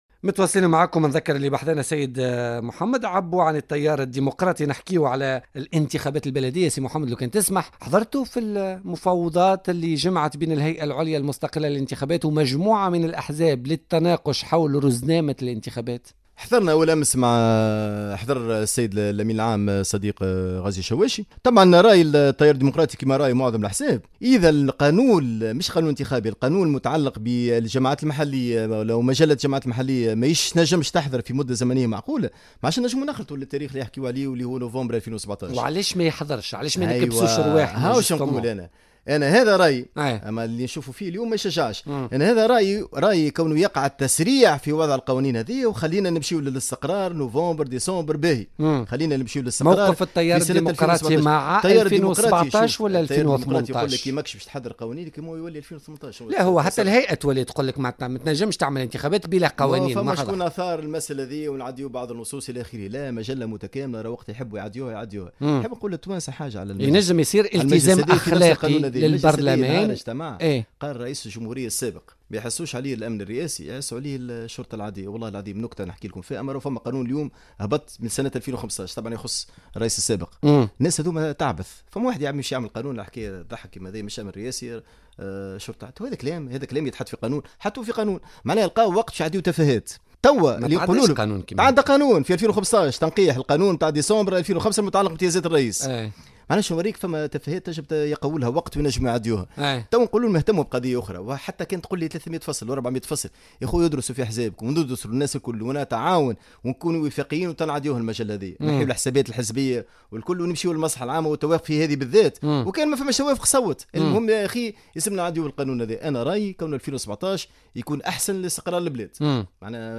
أكد القيادي بحزب التيار الديمقراطي محمد عبو ضيف بولتيكا اليوم الخميس 16 مارس 2017 أنه يملك معطيات عن تمويلات أجنبية لأحزاب سياسية في تونس وأنه مستعد للإدلاء بما يملك اذا تم فتح تحقيقات جدية في هذه الشبهات.